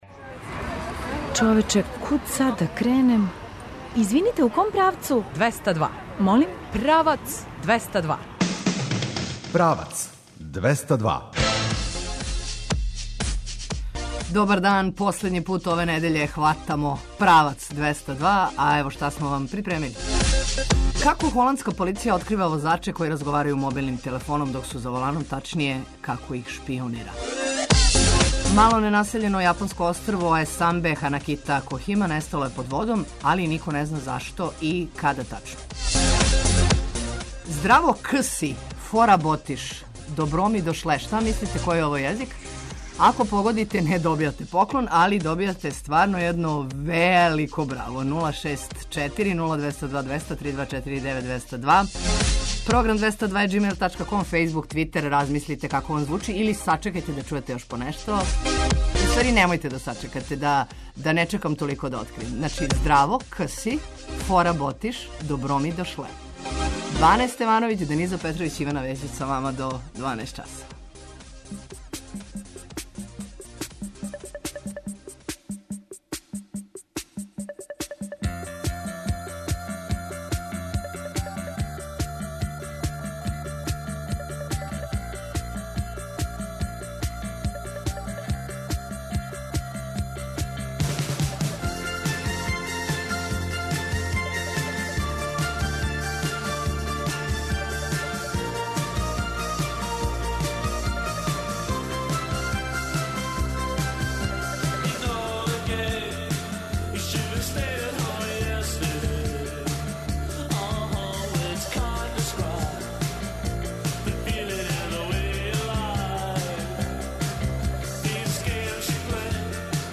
Дубље је село једва 2 км удаљено од Свилајнца али ако дођете са стране тешко да ћете било кога разумети. Одакле су Дубљани, како и да ли они који се ожене или удају у Дубљу савладају језик, да ли га млади говоре сазнаћете од 11 часова а мало ћемо и ми учити дубљански и то од правих учитеља на правом месту - у сеоској кафани.